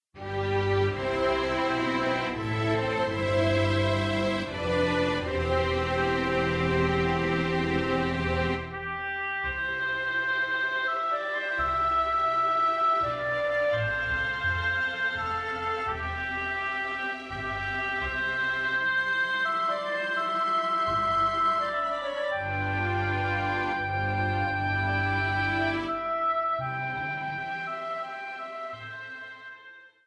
Voicing: Guitar/CD